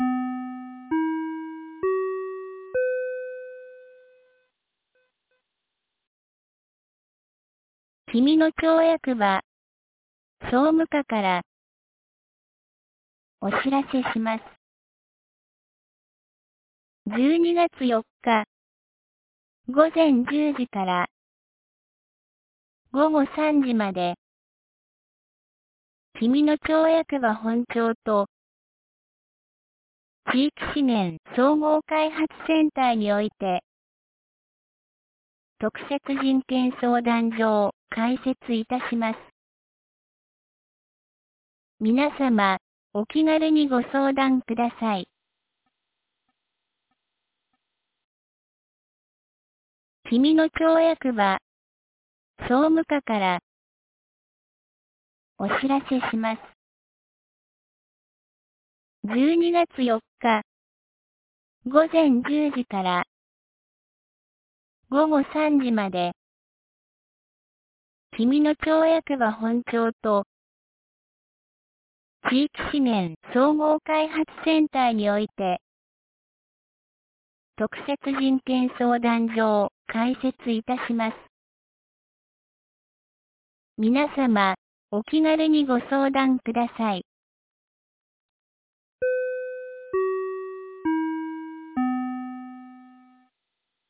2024年12月31日 17時12分に、紀美野町より小川地区へ放送がありました。